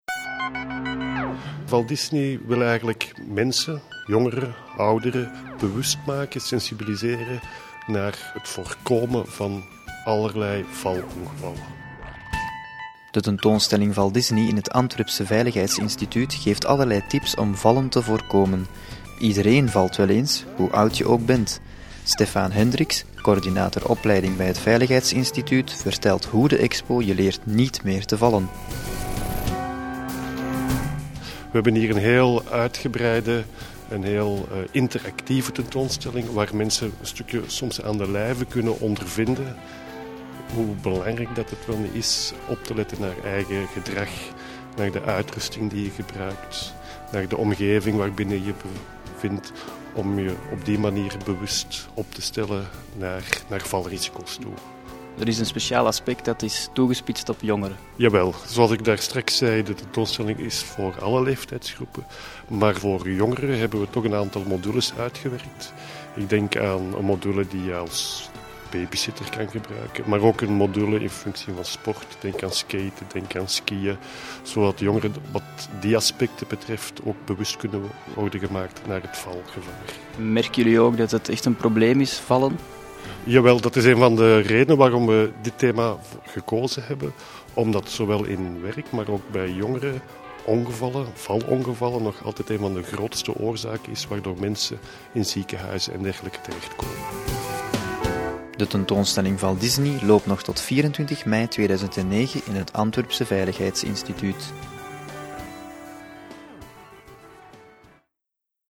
Een gesprek met